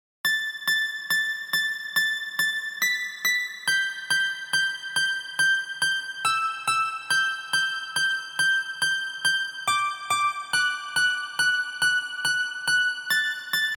恍惚合成器3
描述：霍特恍惚合成器3
Tag: 140 bpm Trance Loops Synth Loops 2.31 MB wav Key : Unknown